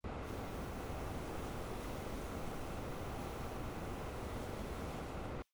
房间内部场景1.wav